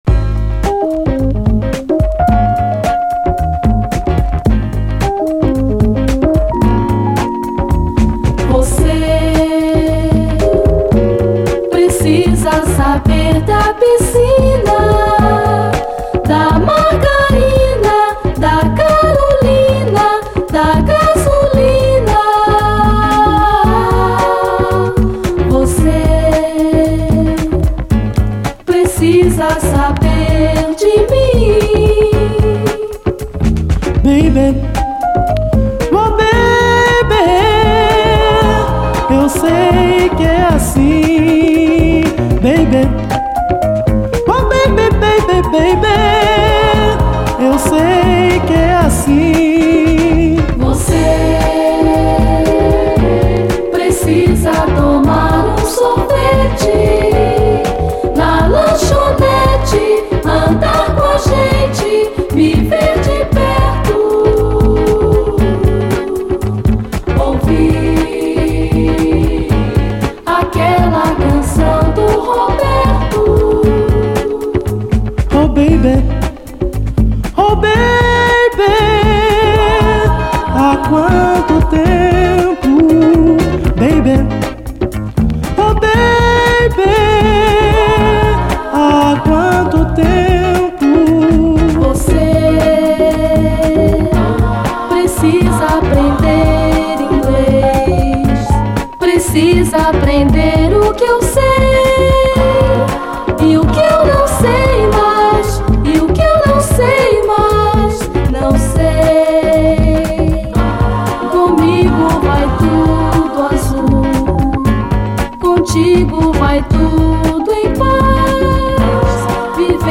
SOUL, 70's～ SOUL, BRAZIL